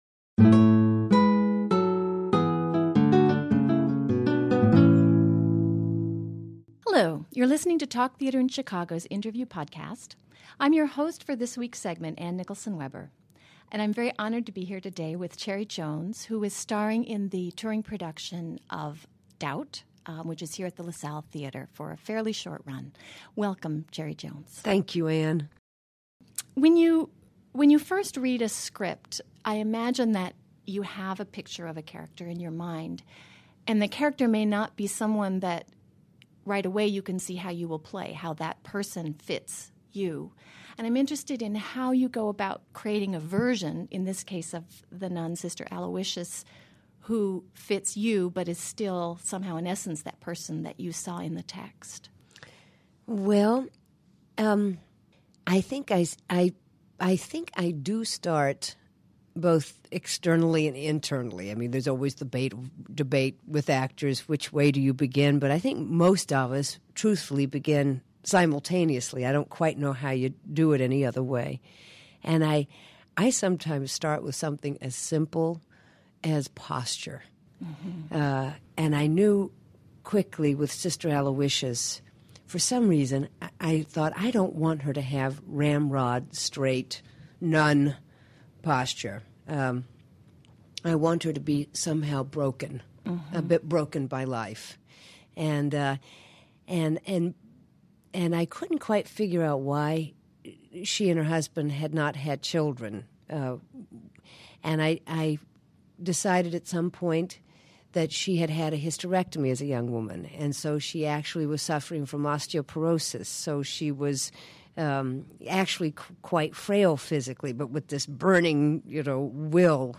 Cherry Jones Talks With Theatre In Chicago - Theatre News - Theatre In Chicago